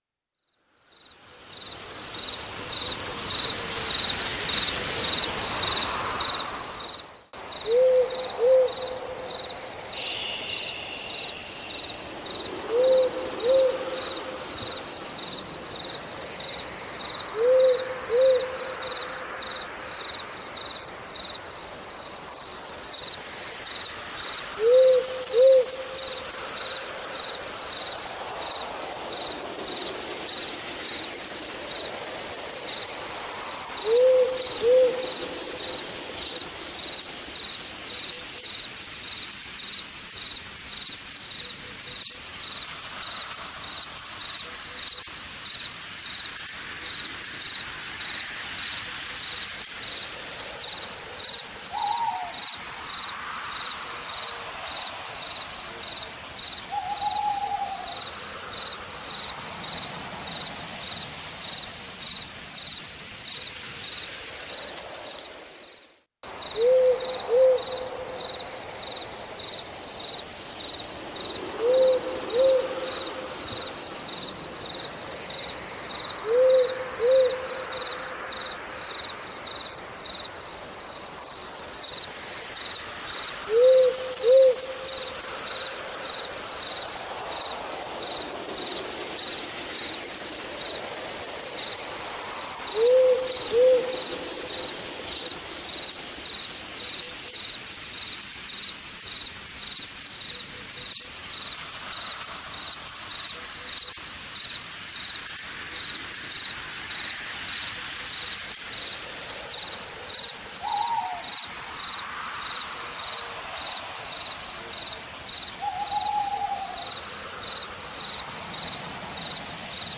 À travers ses albums de slam